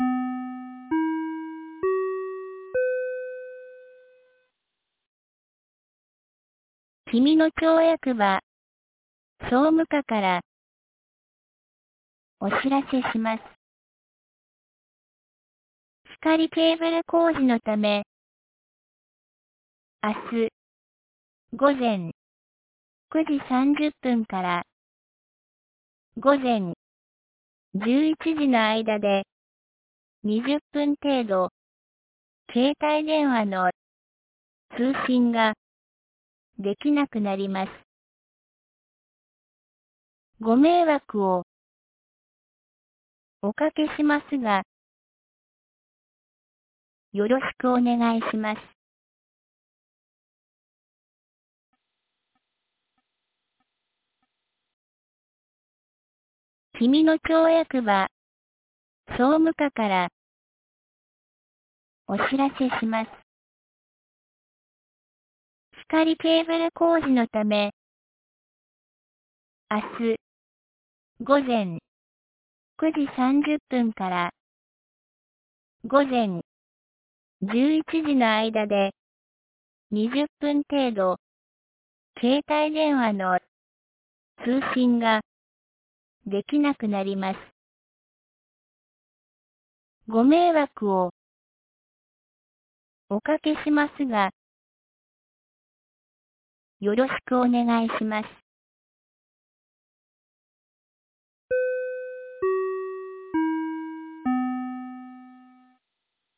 2022年05月25日 17時06分に、紀美野町より長谷毛原地区へ放送がありました。
放送音声